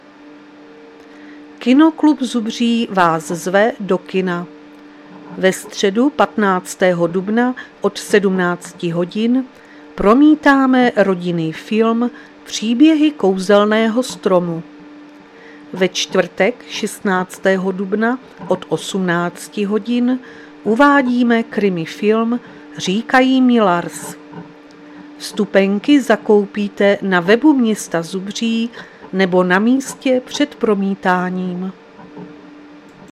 Záznam hlášení místního rozhlasu 13.4.2026